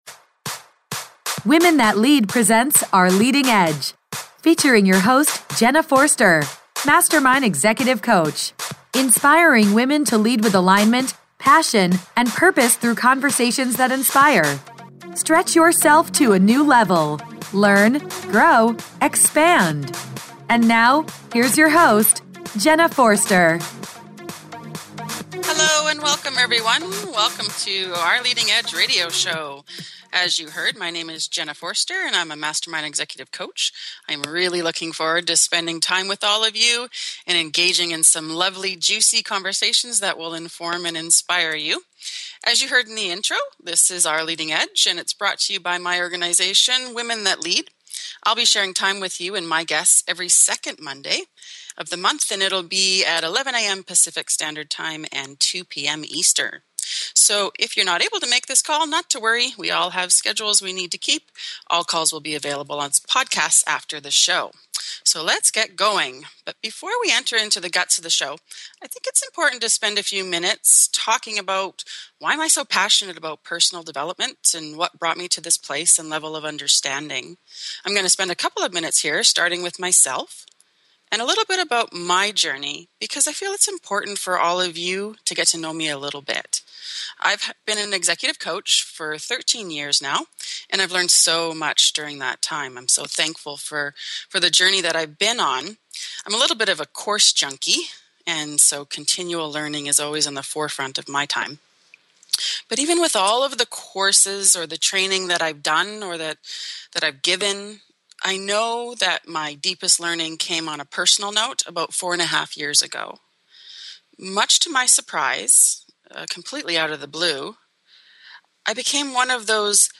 Conversations that inspire women to lead with alignment, passion and purpose.